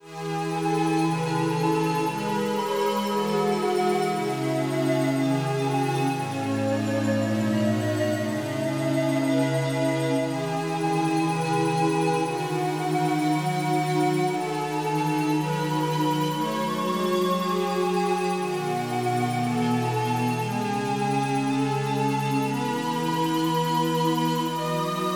Žánr : elektronická hudba
Synth Goblin + Arches